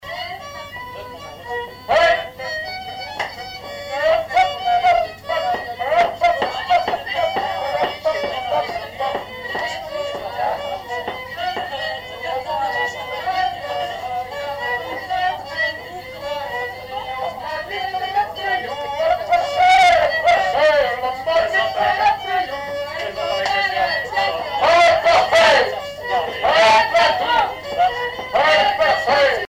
branle : courante, maraîchine
Répertoire d'un bal folk par de jeunes musiciens locaux
Pièce musicale inédite